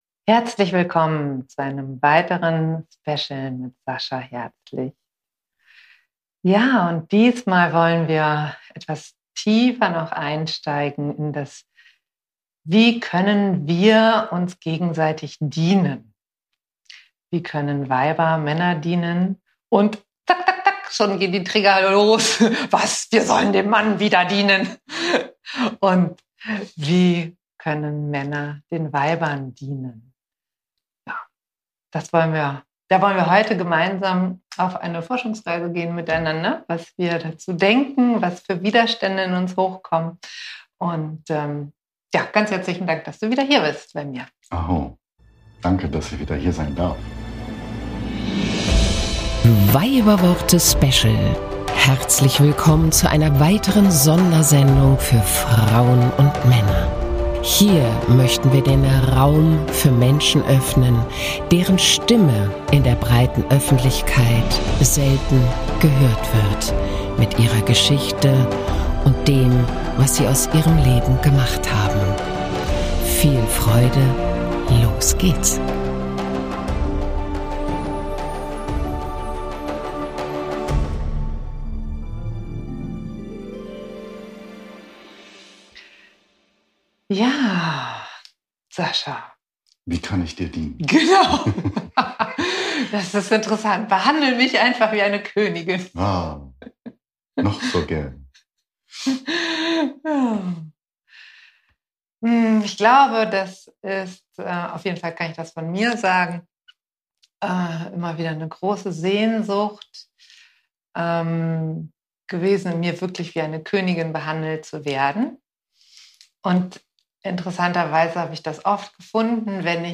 Wir beleuchten im Gespräch zwischen Mann und Weib, wie wir einander im Dienen dienen können um als Männer in der Männlichkeit und als Weiber in der Weiblichkeit anzukommen. Wir können uns gemeinsam dienen uns an all das Vergessene zu erinnern, uns wieder wahrhaftig als Mann und Weib zu sehen und fühlen.